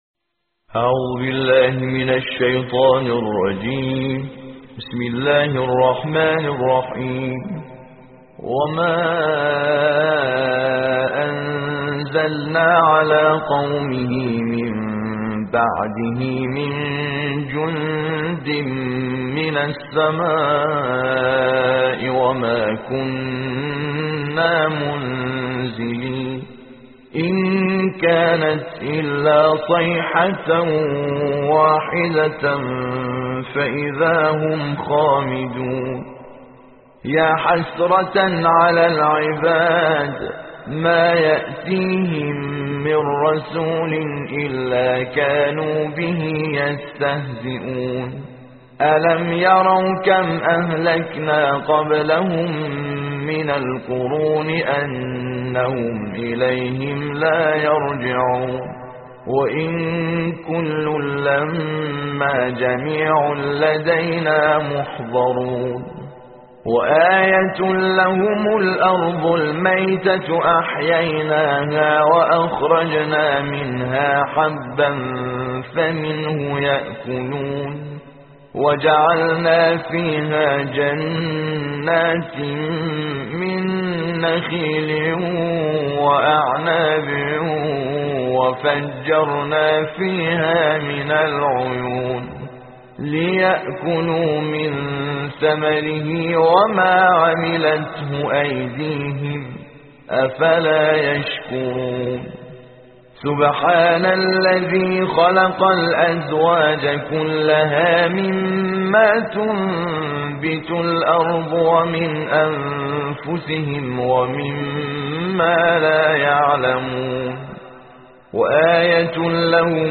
صوت/ ترتیل جزء بیست و سوم قرآن